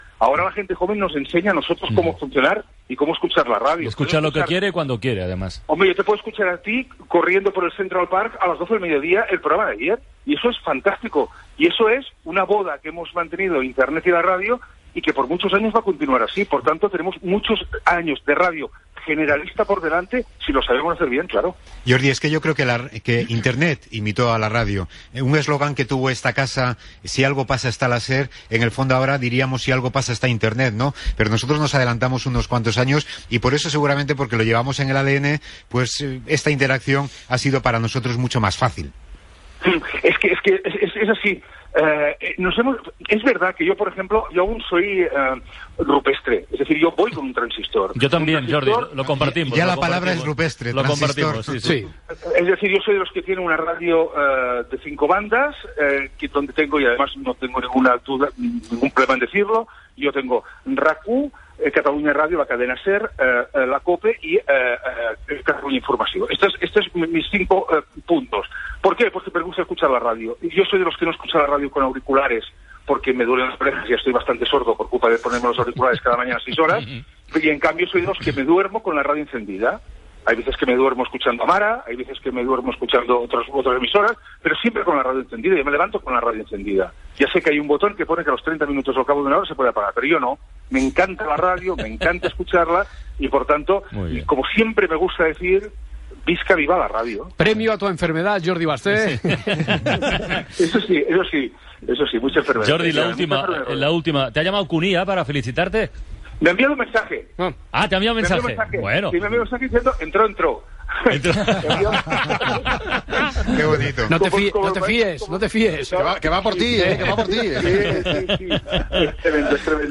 Tarda en la qual s'han comunicat els guanyadors dels Premios Ondas amb entrevistes a Jordi Basté i Julia Otero
Entreteniment
Francino, Carles